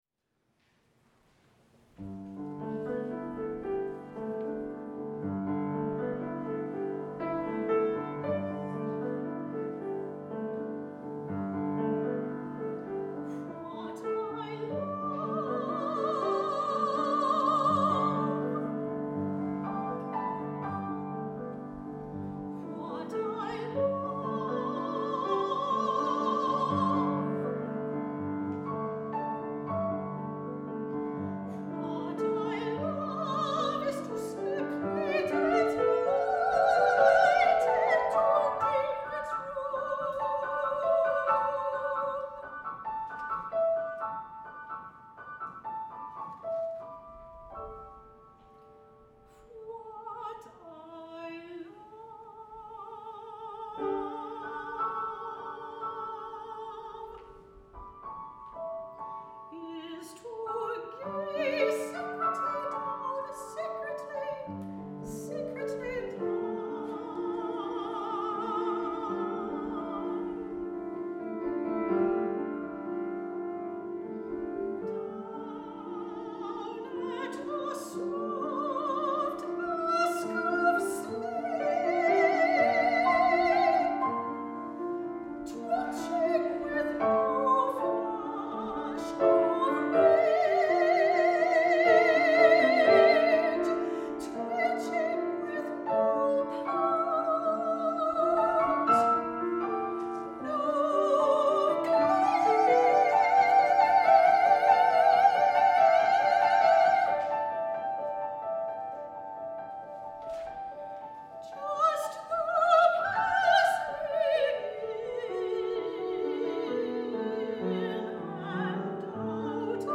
High voice, piano